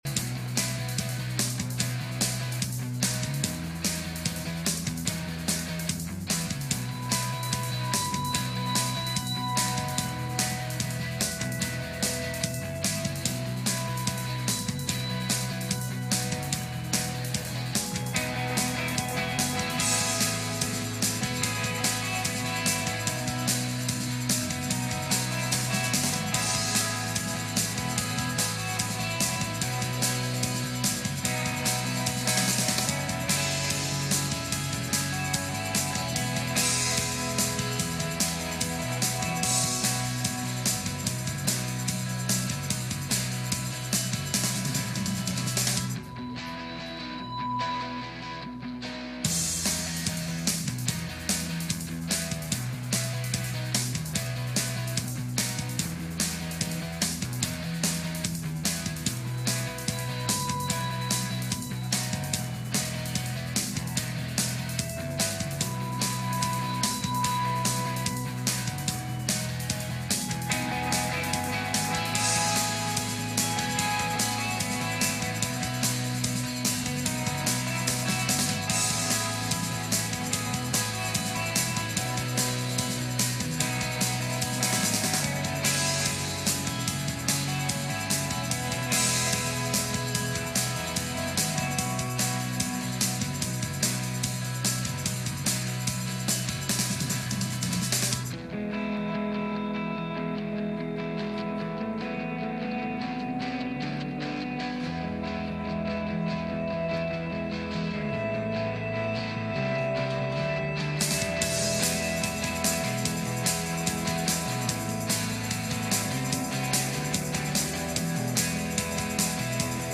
Judges 7:16-18 Service Type: Sunday Morning « A Place Called Wits End